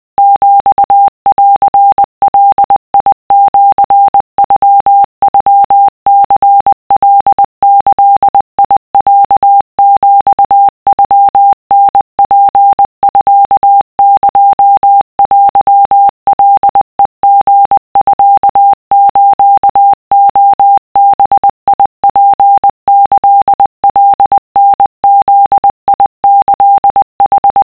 【cw】2【wav】 / 〓古文で和文系〓
とりあえず50CPM (=10WPM)で作ってみた